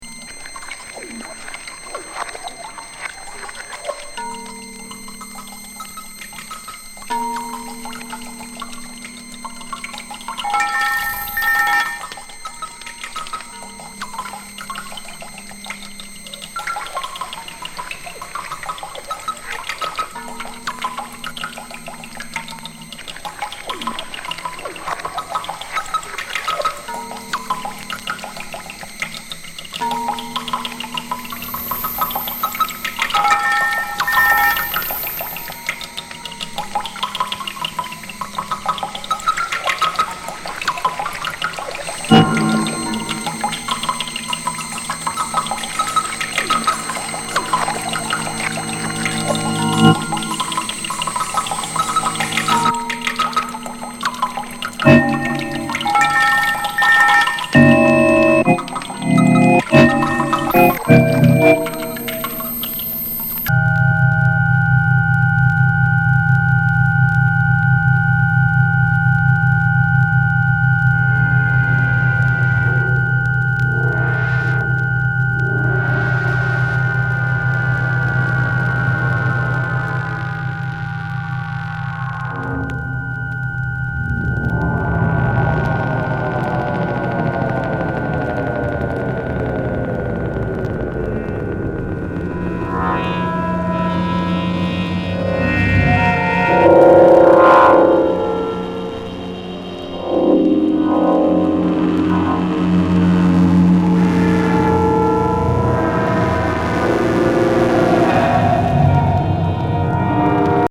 鍾乳洞フィールド録音を電子加工した異界ミュージック・コンクレート70年作！